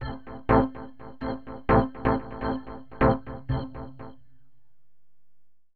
ORGAN021_VOCAL_125_A_SC3(L).wav